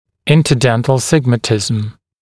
[ˌɪntə’dentl ˈsɪgmətɪzəm][ˌинтэ’дэнтл ‘сигмэтизэм]межзубный сигматизм